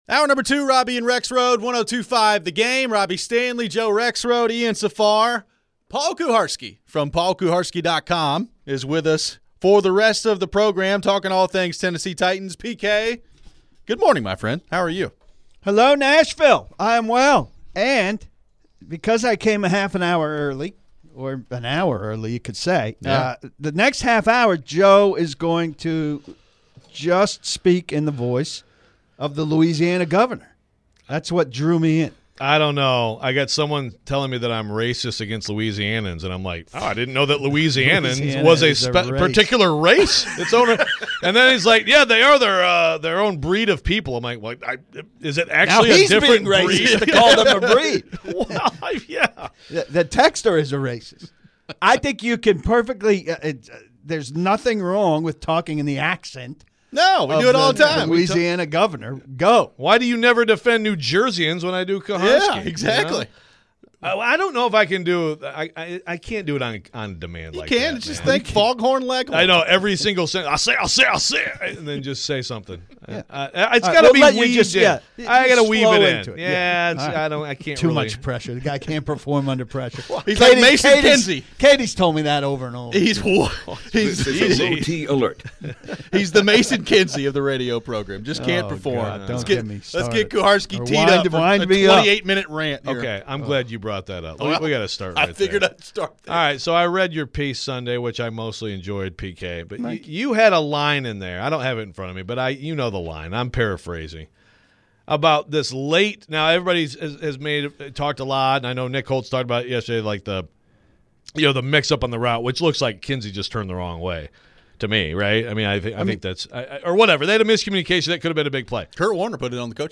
What do we make of Mike McCoy still being so reluctant to go for it on 4th down? We take your phones and questions on the Titans. Should we be worried about the leadership from Simmons?